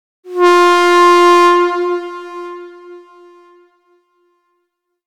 airhorn-short
air-horn alarm alert horn train warning sound effect free sound royalty free Sound Effects